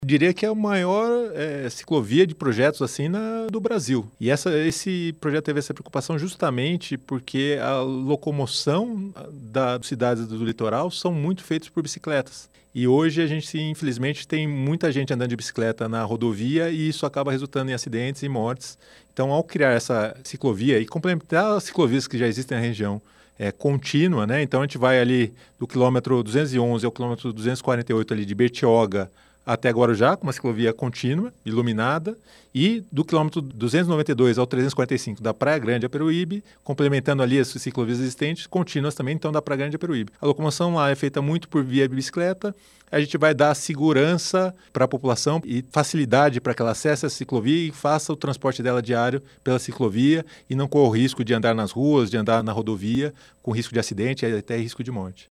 O secretário de parcerias em investimentos do Estado de São Paulo, Rafael Benini, fala a respeito: